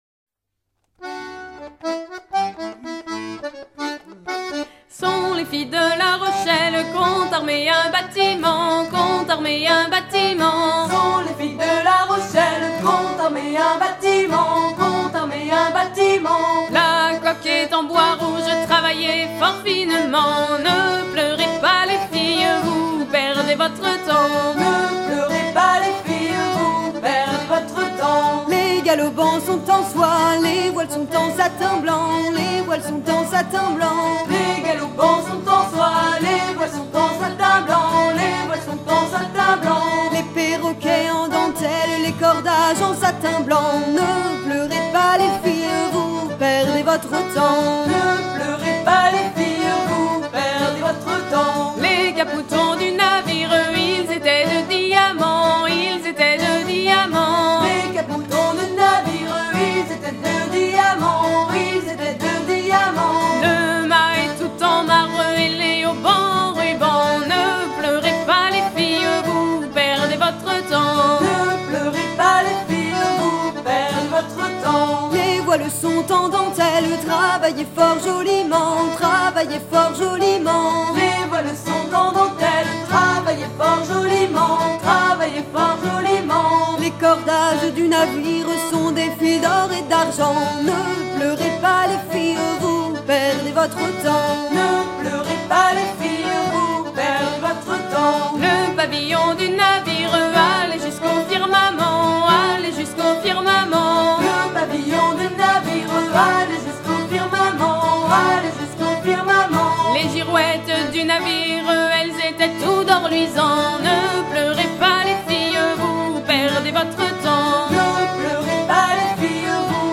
à virer au cabestan ; danse : laridé, ridée ;
Pièce musicale éditée